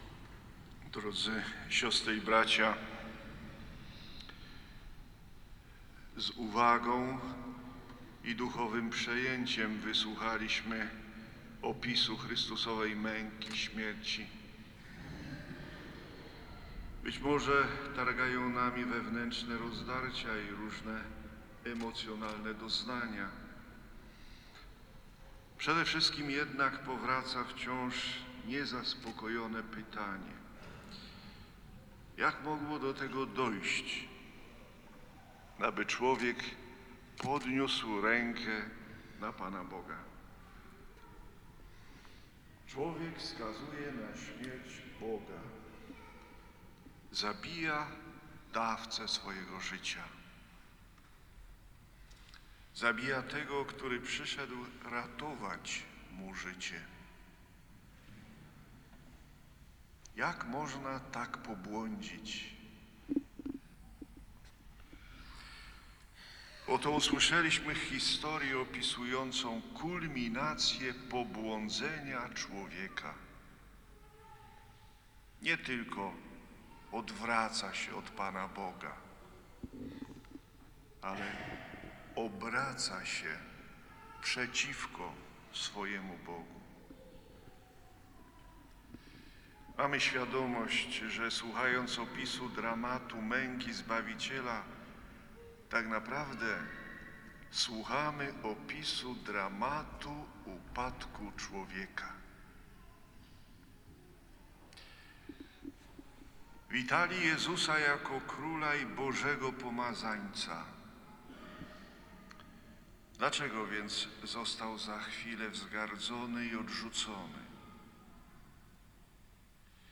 Niedziela Palmowa 2026 - Homilia abpa Józefa Górzyńskiego